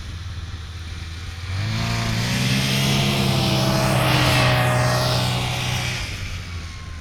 Internal Combustion Subjective Noise Event Audio File (WAV)
madison_ic_2008.wav